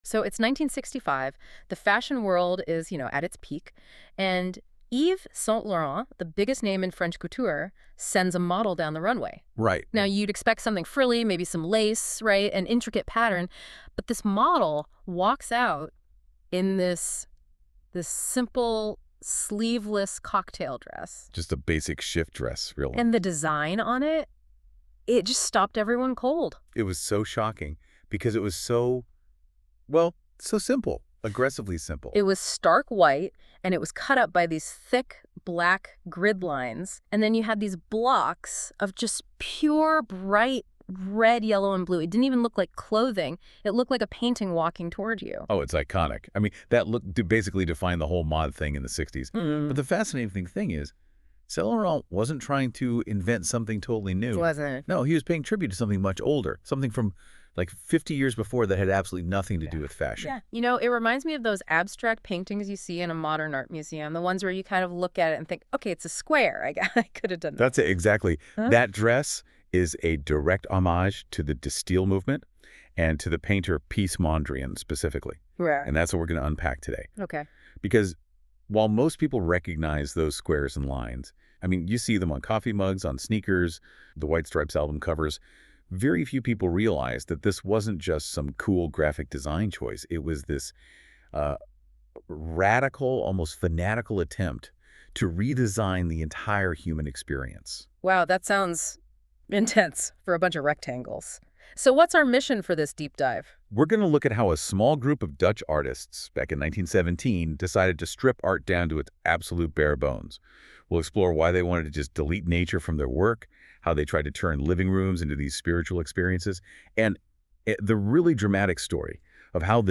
A discussion on De Stijl 1917-1931 (created by NotebookLM from my notes):